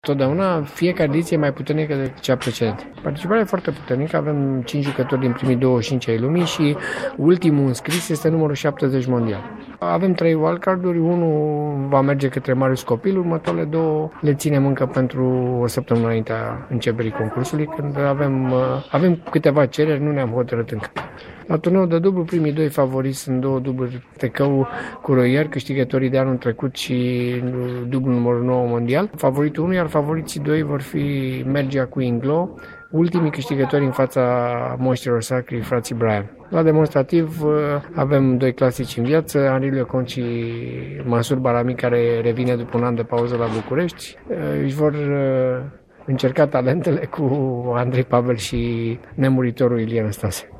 La conferinţa de presă de azi